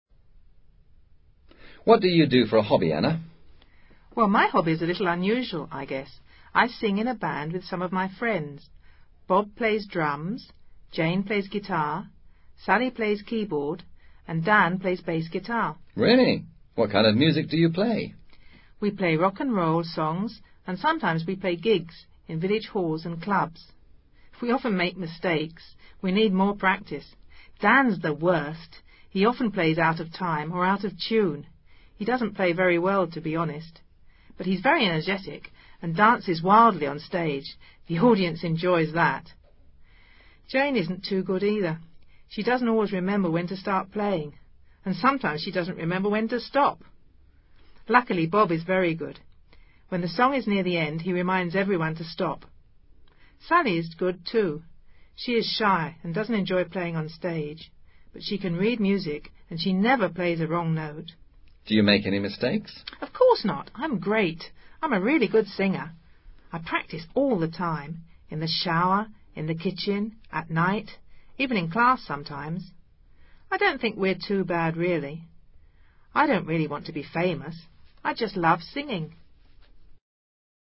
Diálogo en el que una joven habla de su grupo de música.